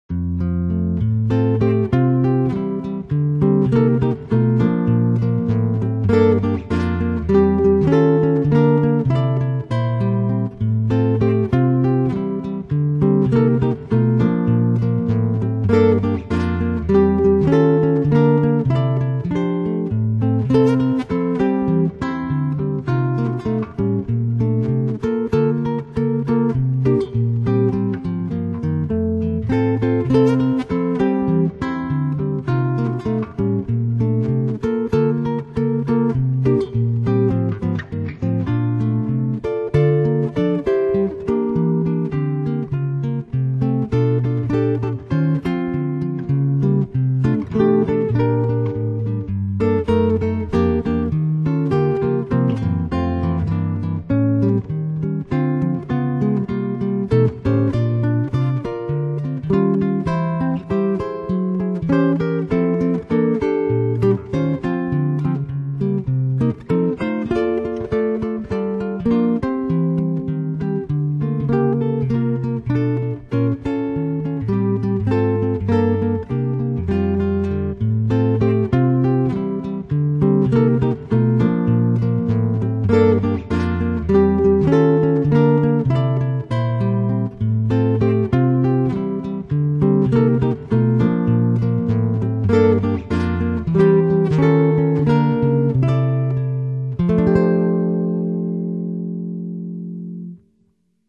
played in the key of F.